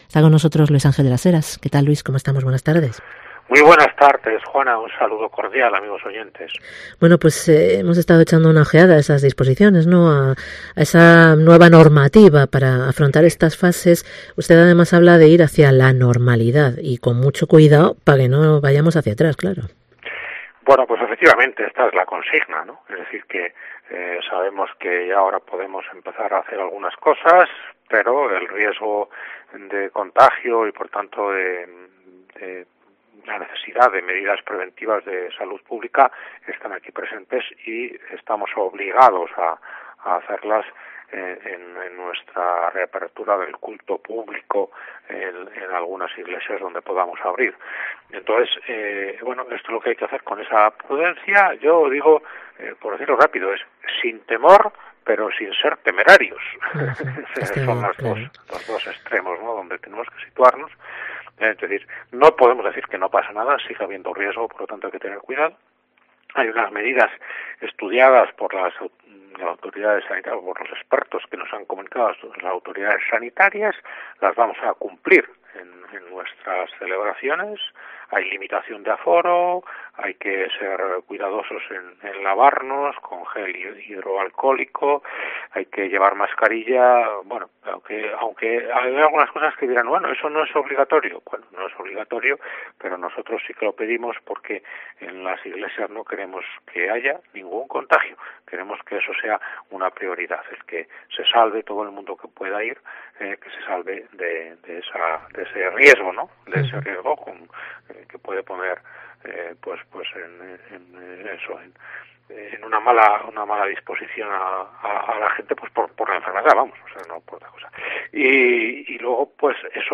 Entrevista con LUIS ÁNGEL DE LAS HERAS